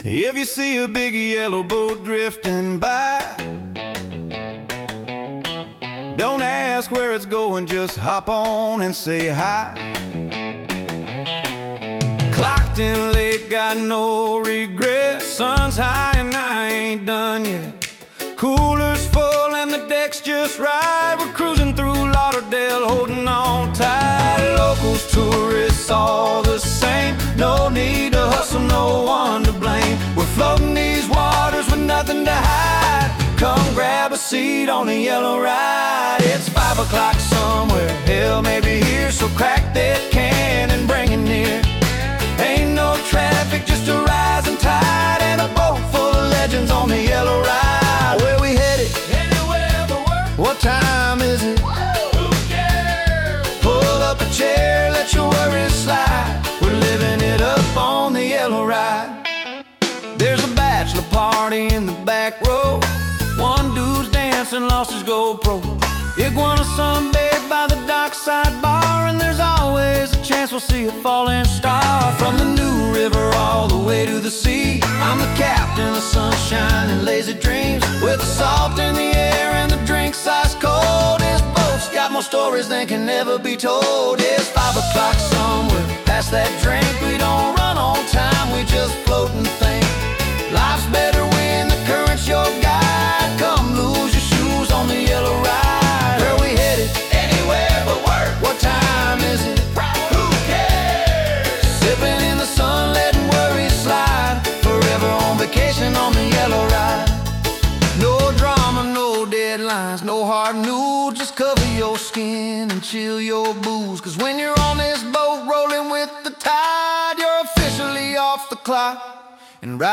Country Soul